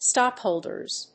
/ˈstɑˌkholdɝz(米国英語), ˈstɑ:ˌkhəʊldɜ:z(英国英語)/